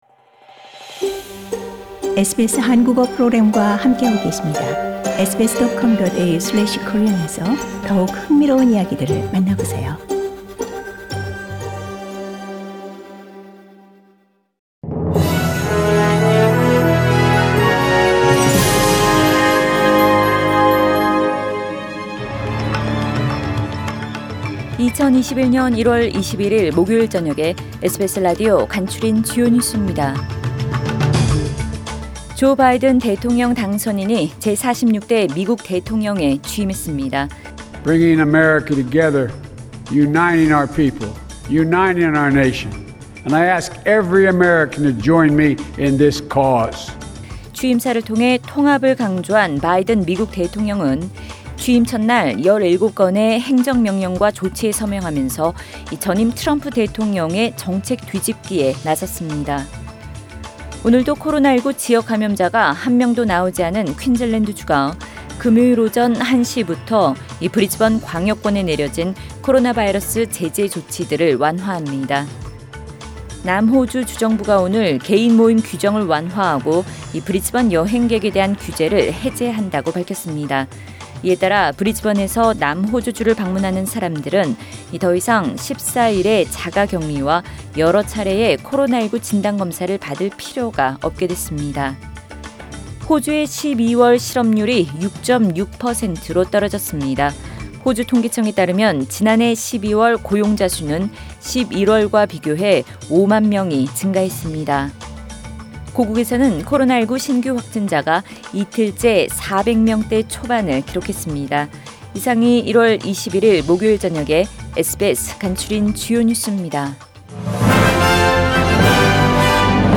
SBS News Outlines…2021년 1월 21일 저녁 주요 뉴스